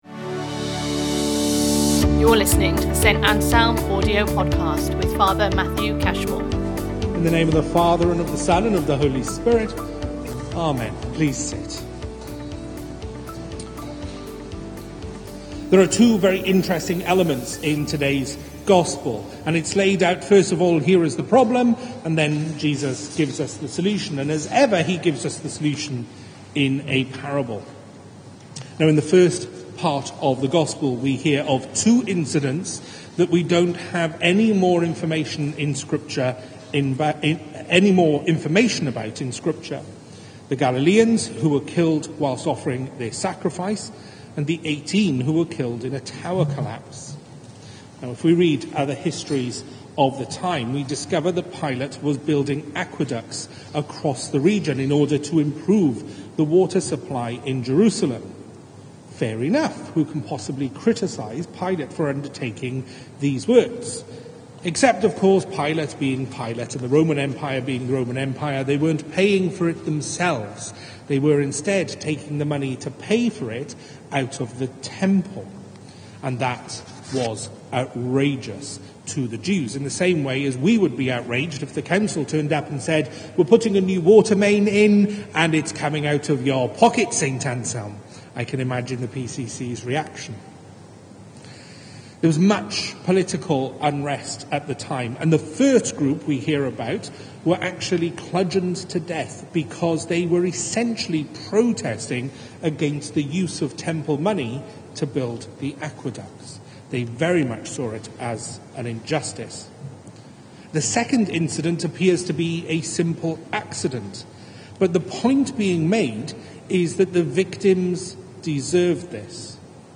Sin Series Sunday Sermons Book Luke Watch Listen Read Save Luke 13:1-9 And so reflect this lent on your individual sin.